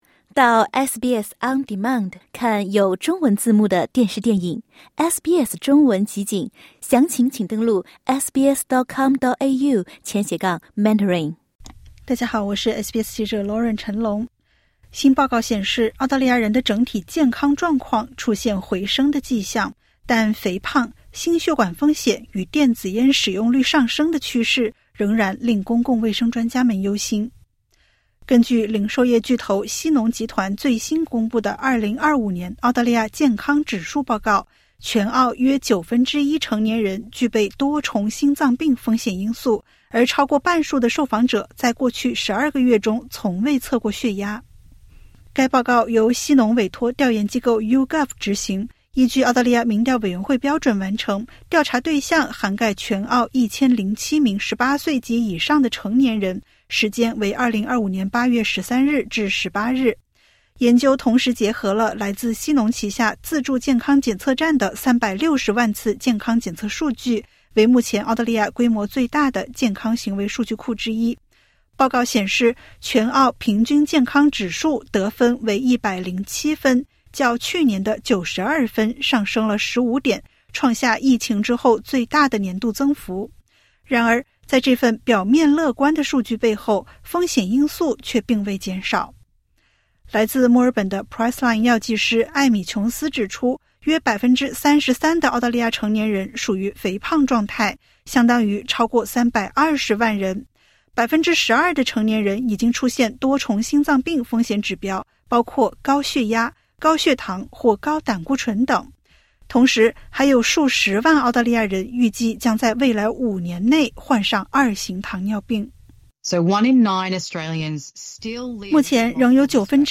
新报告显示，澳大利亚人的整体健康状况出现回升迹象，但肥胖、心血管风险与电子烟使用率上升的趋势，仍令公共卫生专家忧心。点击 ▶ 收听完整报道。